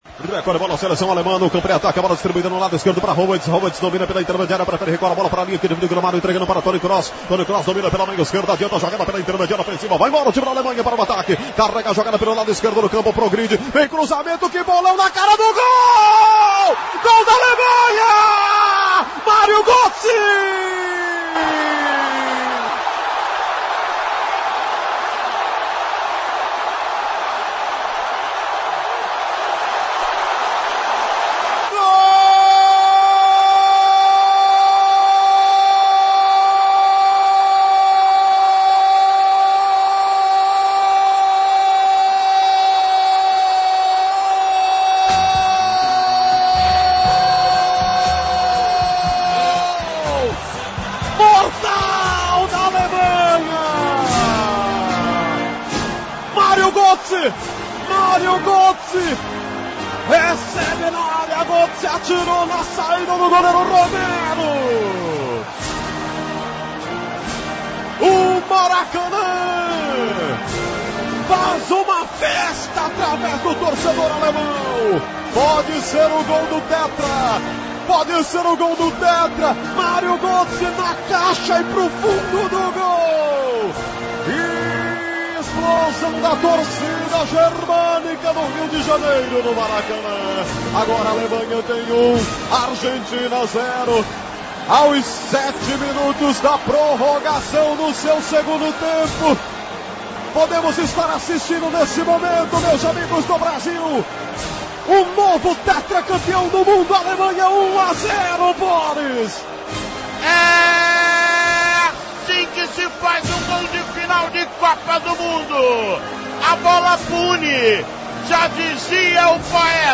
GOL-DA-ALEMANHA.mp3